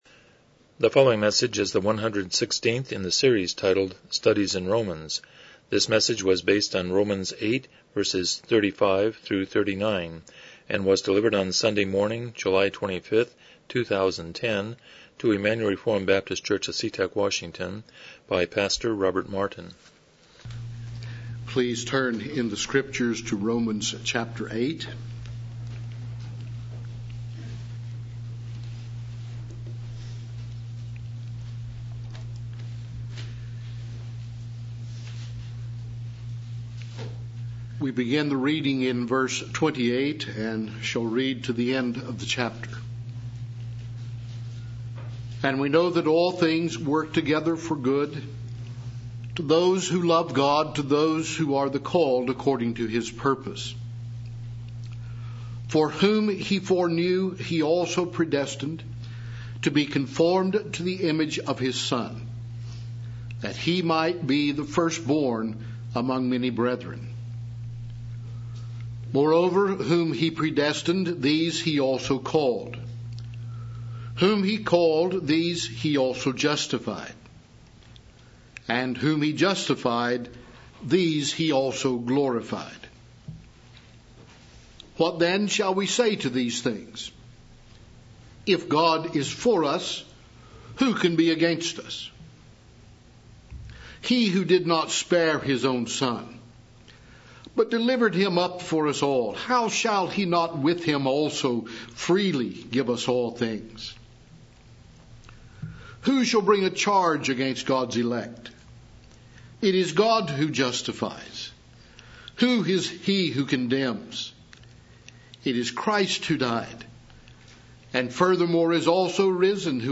Romans 8:35-39 Service Type: Morning Worship « 101 Chapter 19.4